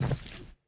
Footstep_Concrete 01.wav